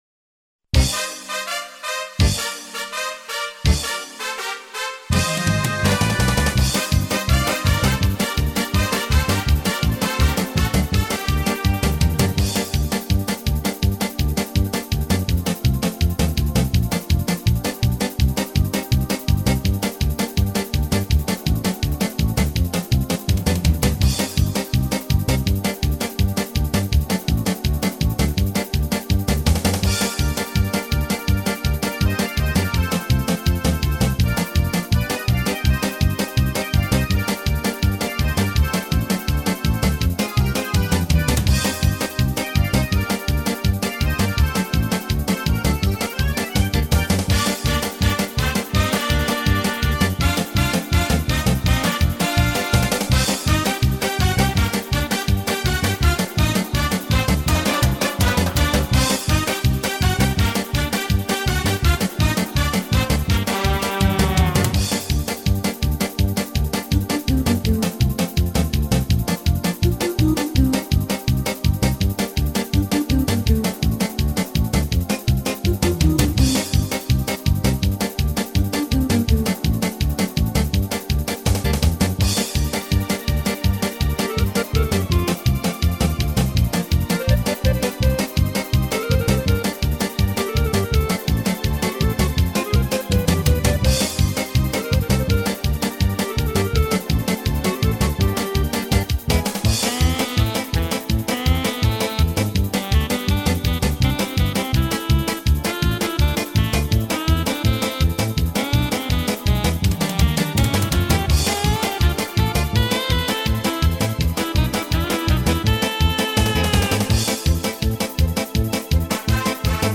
Фонограма-мінус (mp3, 192 kbps). весільна полька